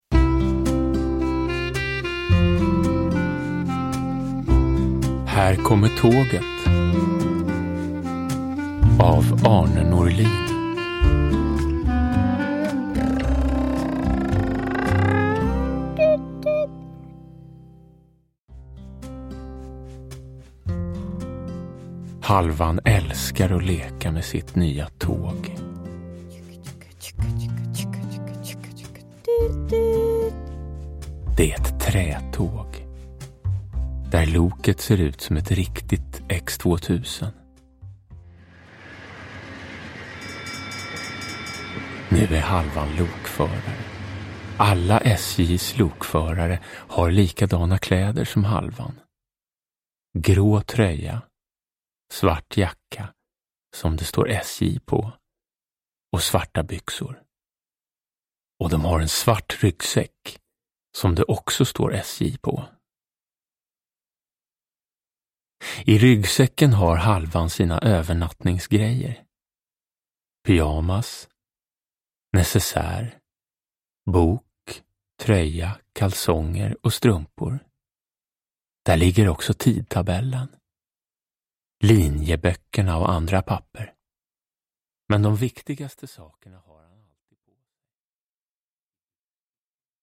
Här kommer tåget – Ljudbok – Laddas ner
Uppläsare: Jonas Karlsson